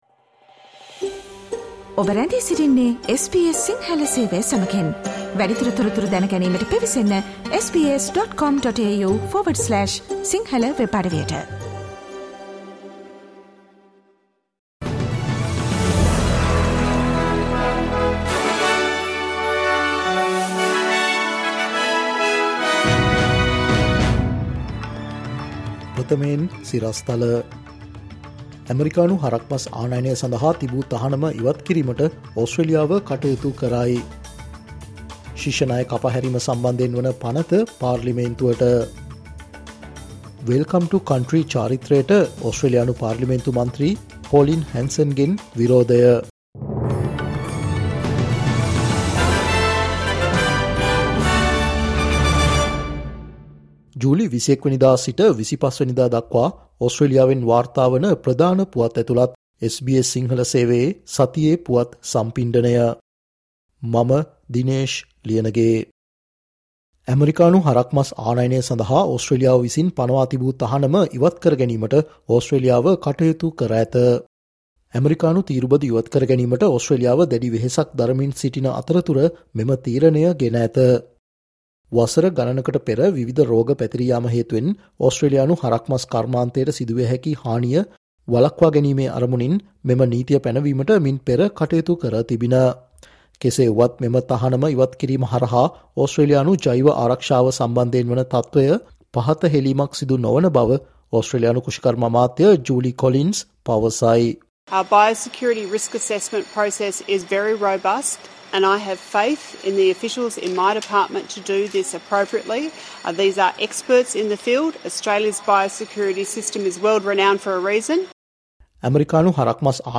'මේ සතියේ ඔස්ට්‍රේලියාව': SBS සිංහල ගෙන එන සතියේ ඕස්ට්‍රේලියානු පුවත් සම්පිණ්ඩනය, ජූලි 21 - ජූලි 25